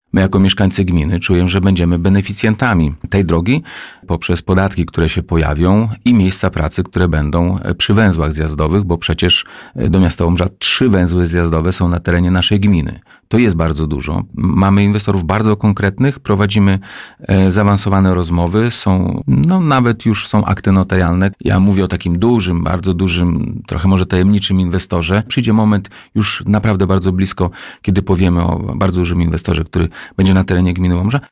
Pojawiły się akty notarialne, trwają szczegółowe rozmowy – niebawem powinniśmy dowiedzieć się konkretnie jaki inwestor dzięki Via Baltice pojawi się na terenie gminy Łomża – zapowiedział na antenie Radia Nadzieja Piotr Kłys.